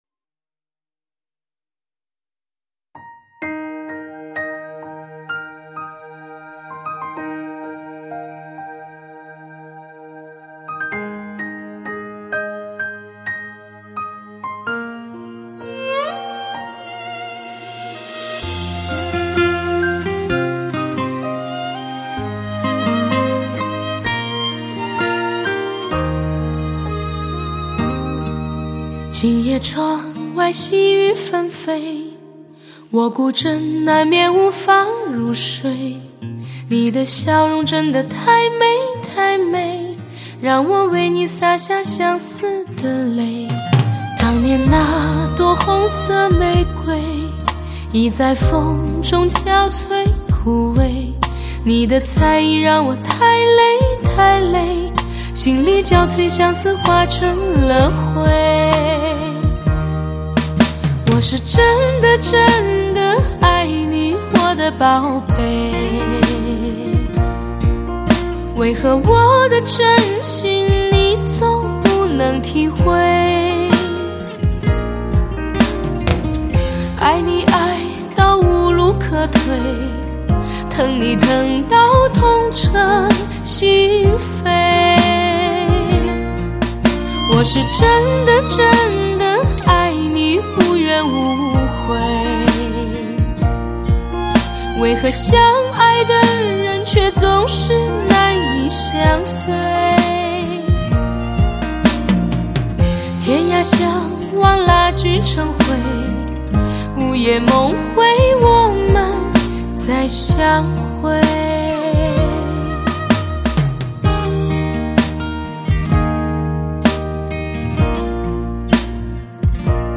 红尘阡陌，摇曳前世过往，柔声清调，
淳朴流畅，明快抒情，雅俗共赏，怡情悦性，传承经典